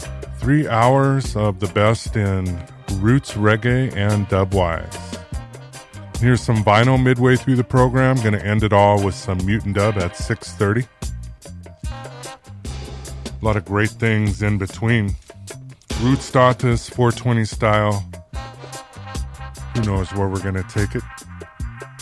gospel cover